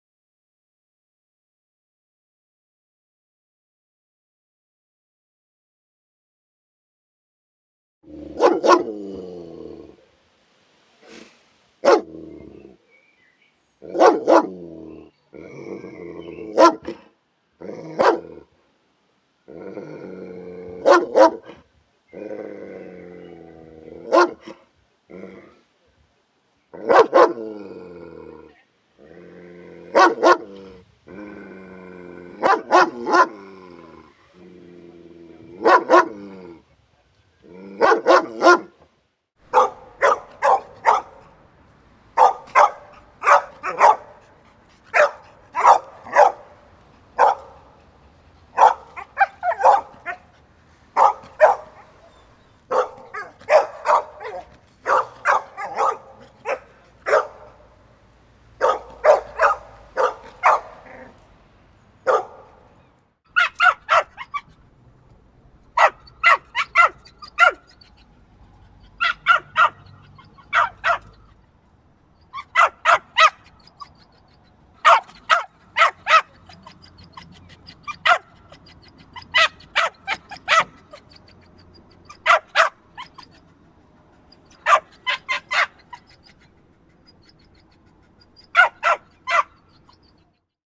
DOG BARKING SOUND EFFECTS sound effects free download